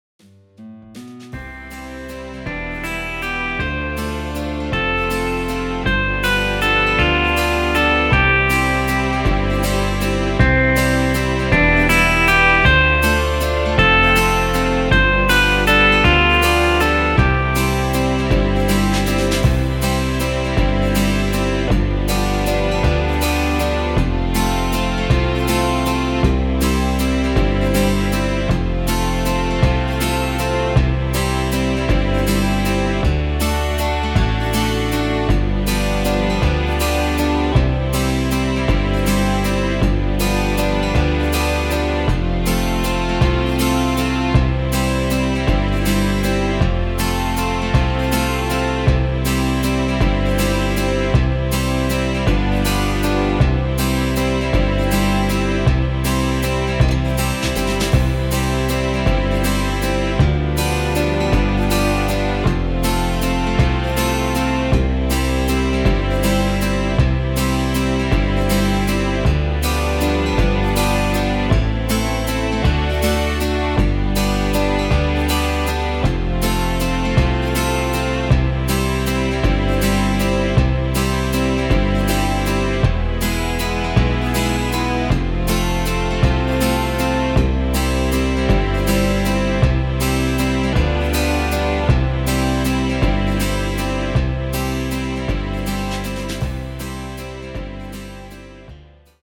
• Tonart:  C Dur, G Dur (Originaltonart)
• Art: Playback vollinstrumentiert
• Das Instrumental beinhaltet NICHT die Leadstimme
Klavier / Piano